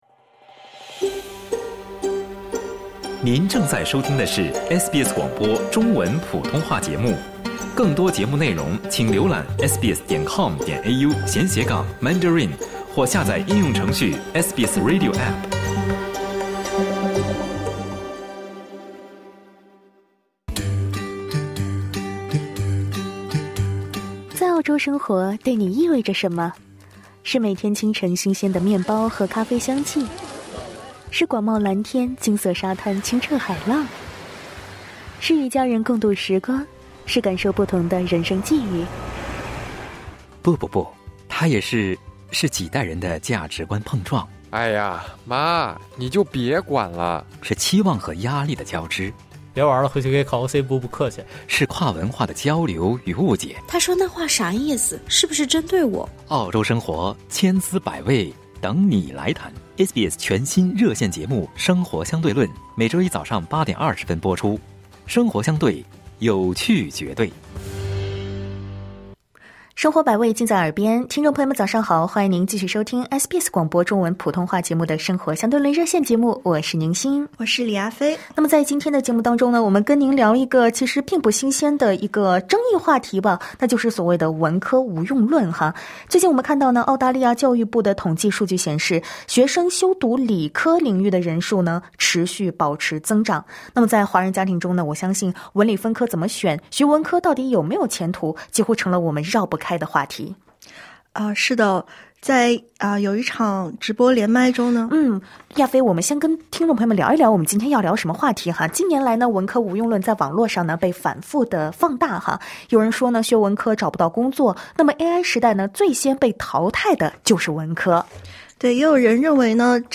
《生活相对论》热线节目 每周一早晨8:30在SBS普通话电台播出。